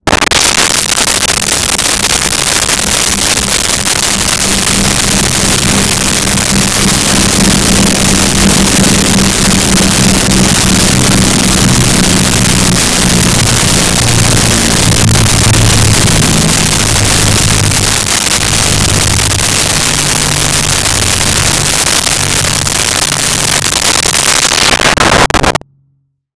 Propeller Plane Pass By
Propeller Plane Pass By.wav